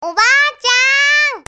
そんなババアに駆け寄ってやりきれない声で叫ぶキャロル。